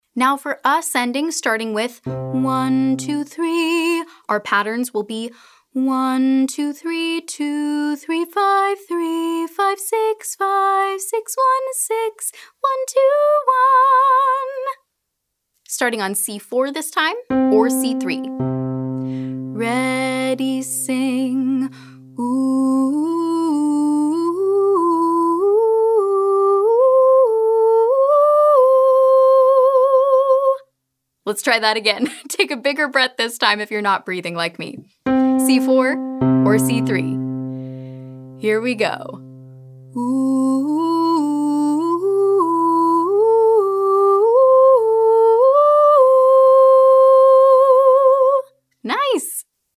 • ascending pentatonic 3-note riffs